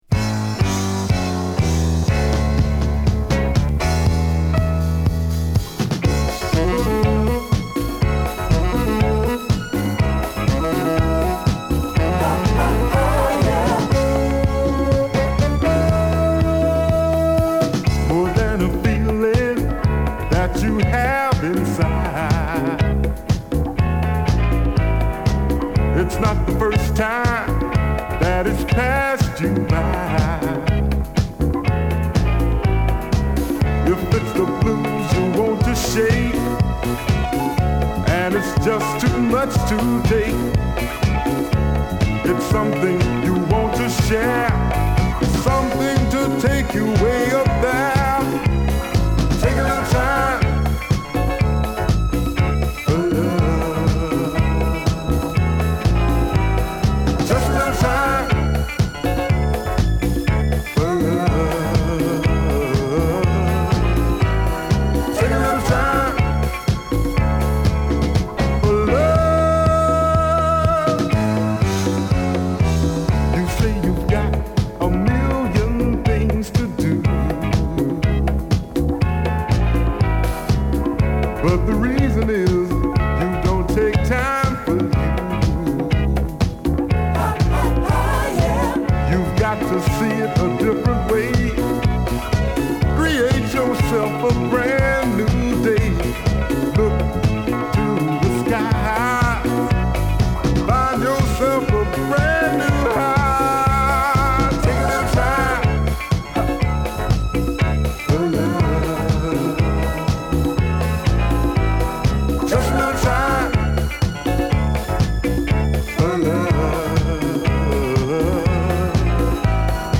アラバマ産モダンソウル！